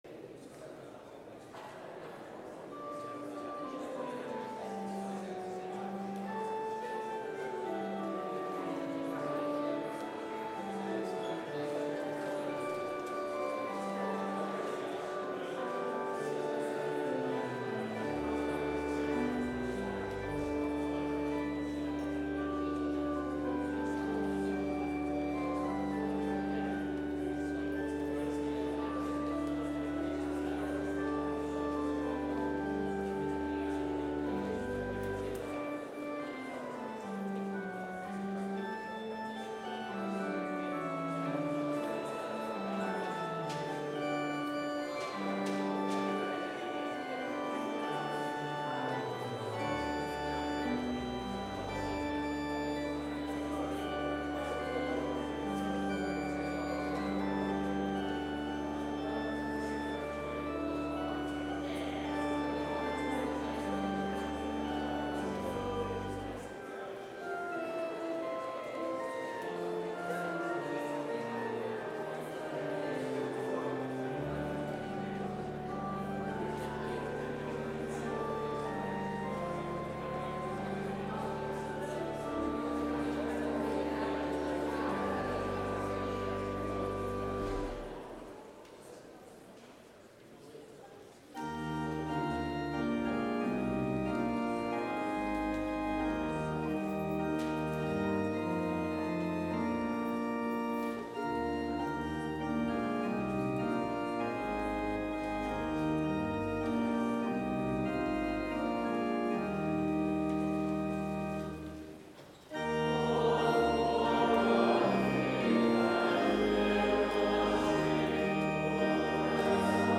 Complete service audio for Chapel - Tuesday, April 9, 2024